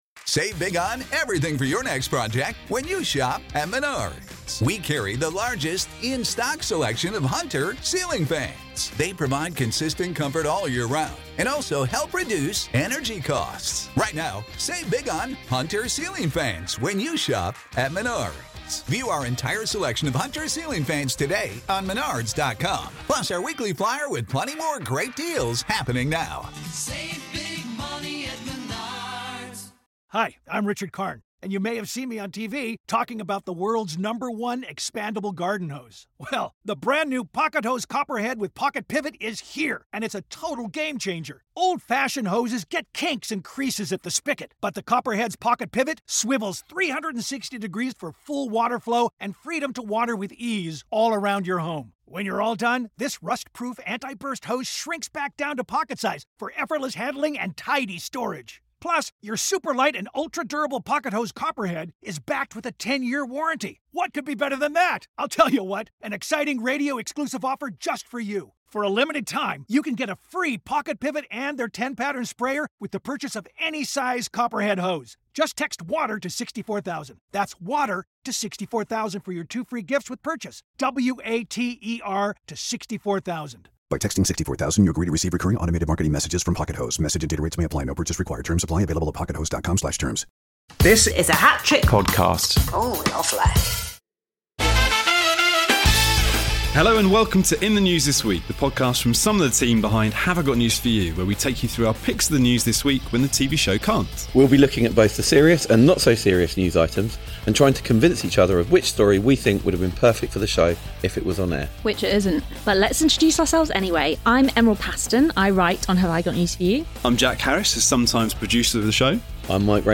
Join some of the team behind Have I Got News For You as they take you through the serious (but more often not-so-serious) stories In The News This Week.